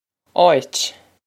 Áit Aw-it
Pronunciation for how to say
This is an approximate phonetic pronunciation of the phrase.